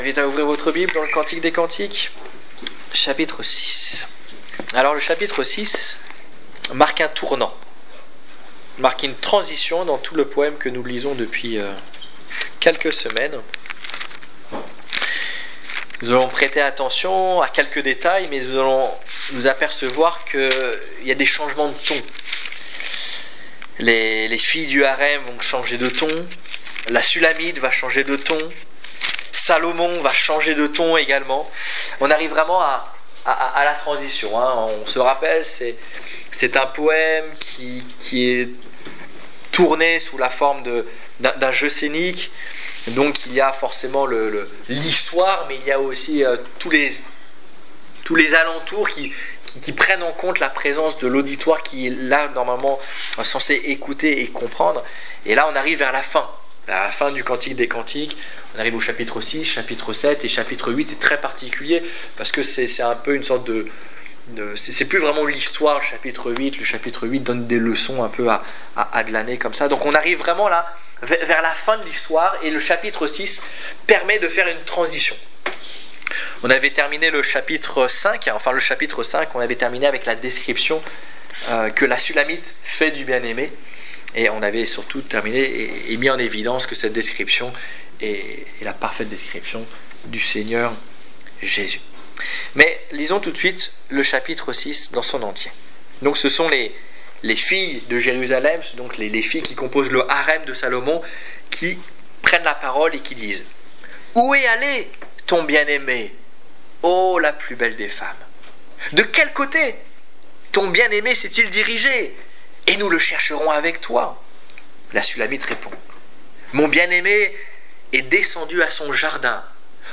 Étude biblique du 16 septembre 2015